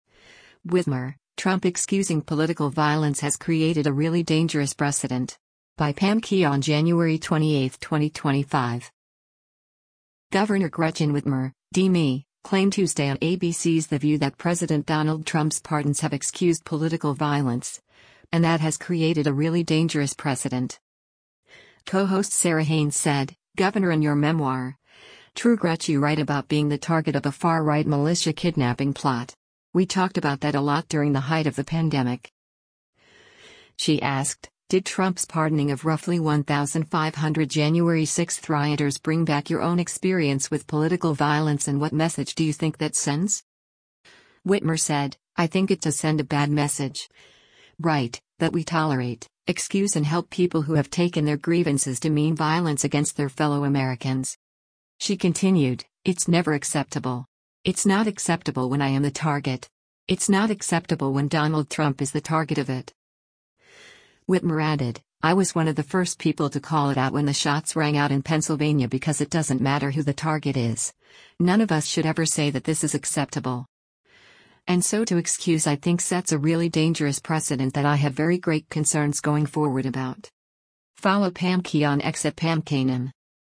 Governor Gretchen Whitmer (D-MI) claimed Tuesday on ABC’s “The View” that President Donald Trump’s pardons have excused political violence, and that has created a “really dangerous precedent.”